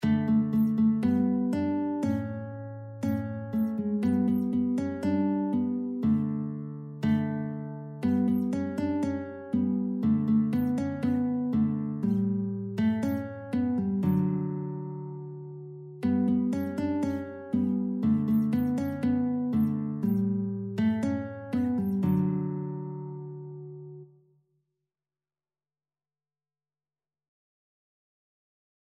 Guitar version
Traditional Music of unknown author.
Allegro (View more music marked Allegro)
2/4 (View more 2/4 Music)
Guitar  (View more Easy Guitar Music)
Classical (View more Classical Guitar Music)